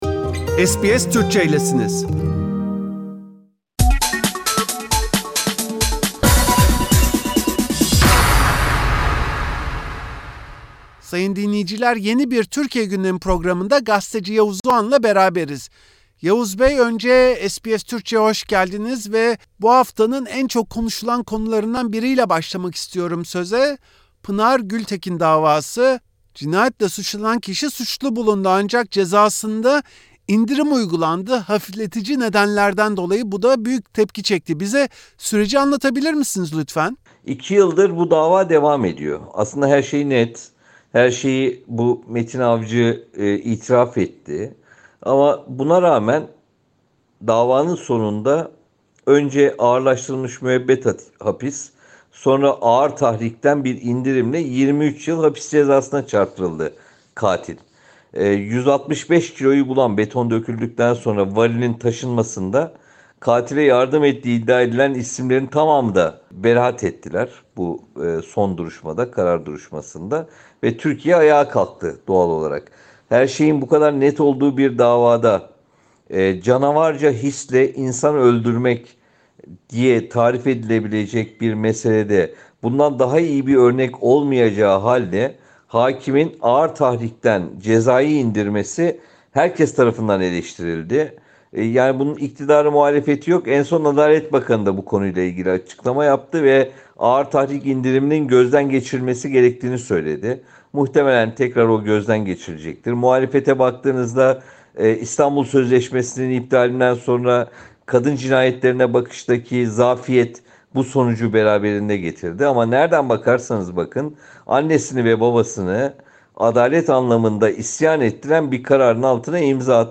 Gazeteci Yavuz Oğhan Türkiye Cumhurbaşkanı Erdoğan’ın Suudi Veliaht Prensi Salman’la görüşmesi ve özellikle ikilinin çektirdiği fotoğraflar üzerine dönen tartışmayı SBS Türkçe’ye değerlendirdi.